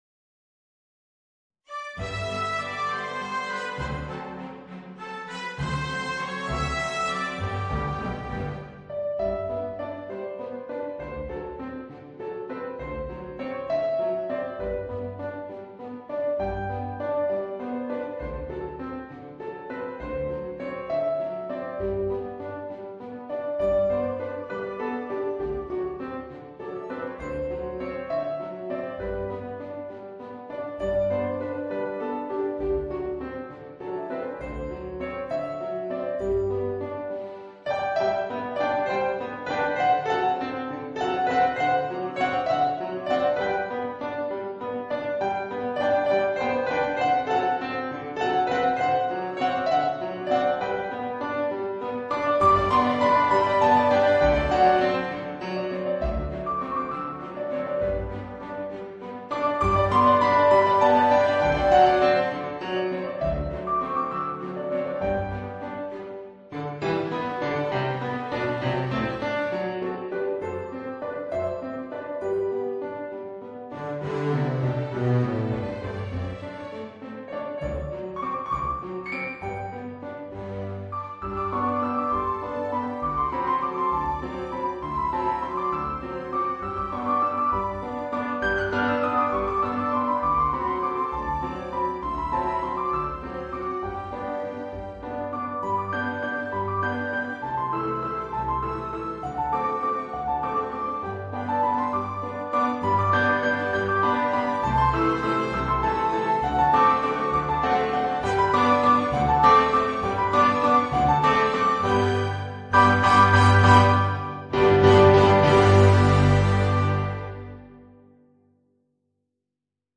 Voicing: Piano and Orchestra